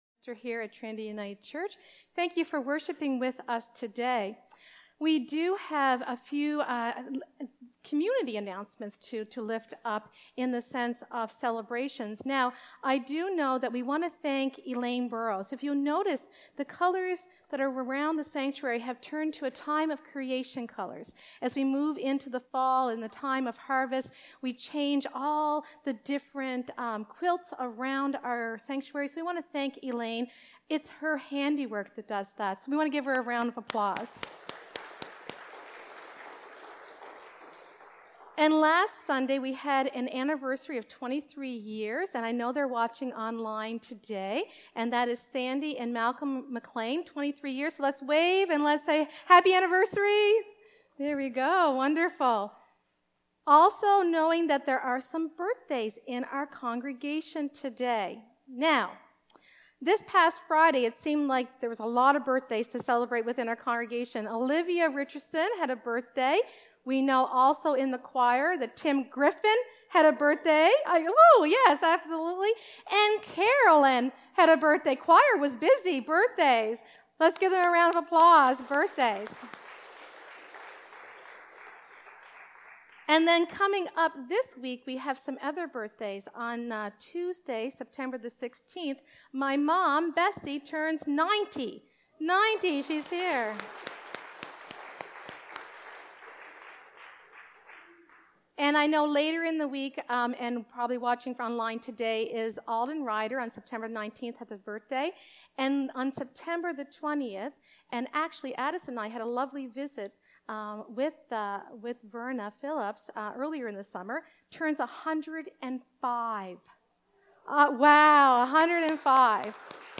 Live Worship Service – September 14th, 2025